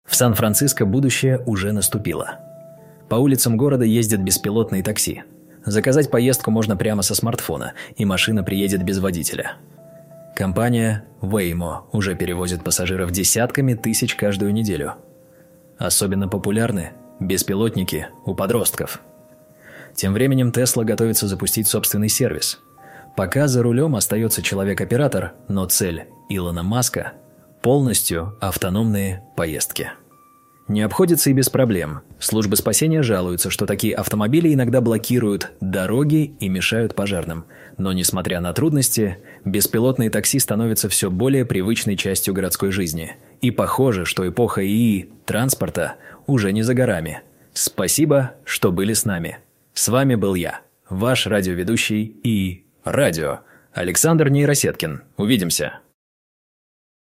Вся музыка сгенерирована ИИ и не только музыка - здесь виртуальные ведущие и голоса в рубриках новости, прогноз погоды, и тп